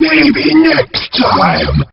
Line of Bubbler in Diddy Kong Racing.